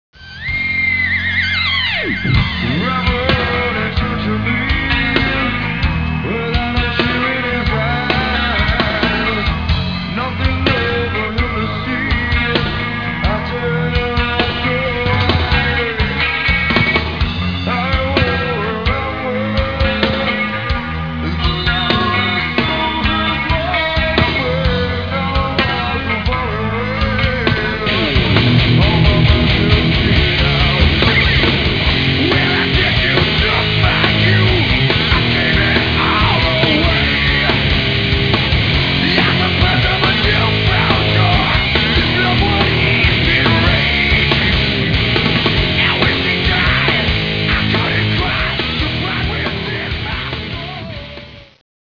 Greatest Band I know of for Metal!